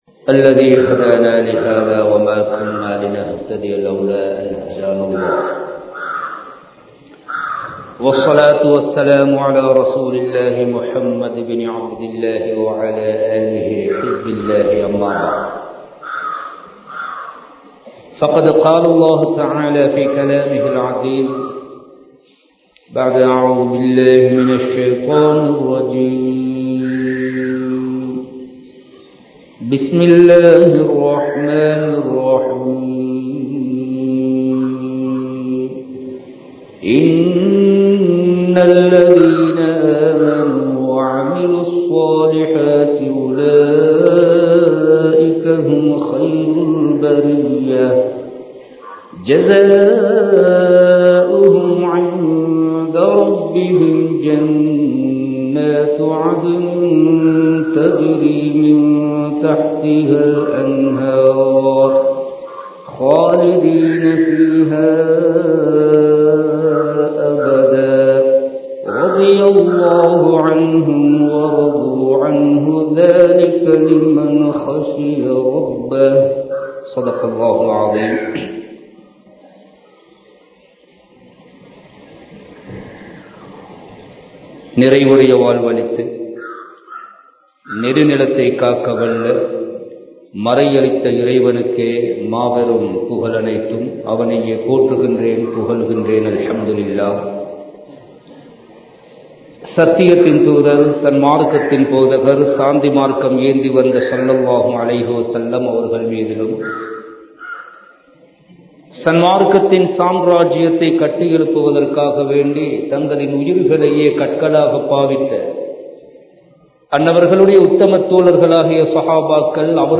உலக வாழ்க்கையின் யதார்த்தம் | Audio Bayans | All Ceylon Muslim Youth Community | Addalaichenai
Kandy, Kattukela Jumua Masjith